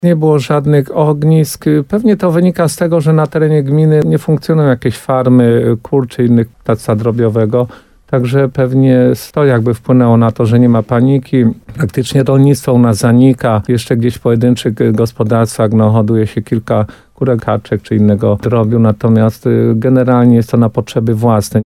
– Chociaż do tej pory nie stwierdzono na terenie gminy Kamienic przypadków zachorowań, powinno się zachować ostrożność – mówi wójt Władysław Sadowski.